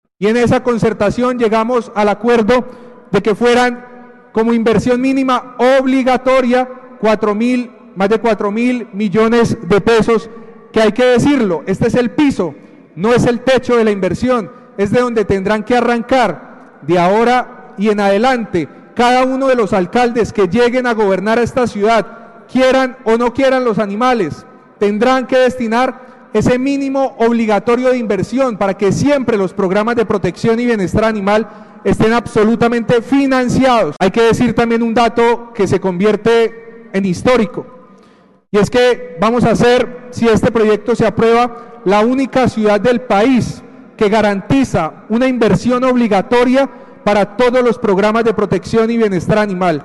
Camilo Machado, concejal de Bucaramanga